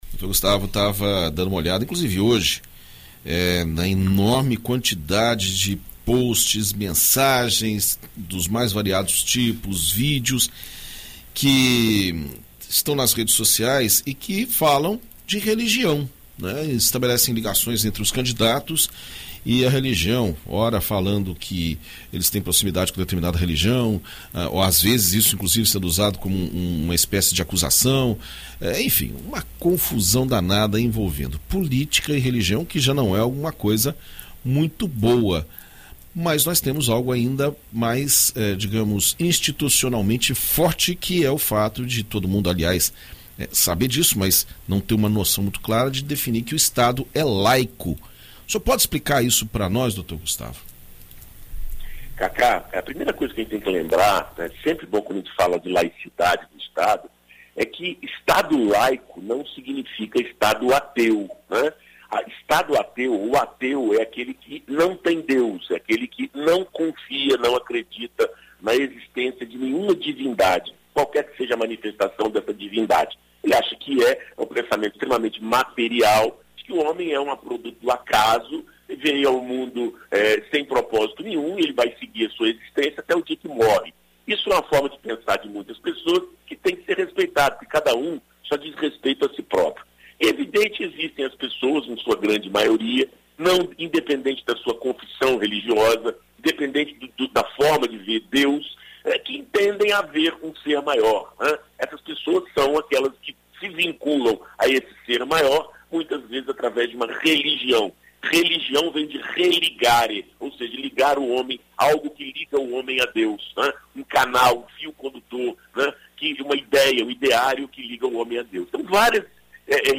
na BandNews FM Espírito Santo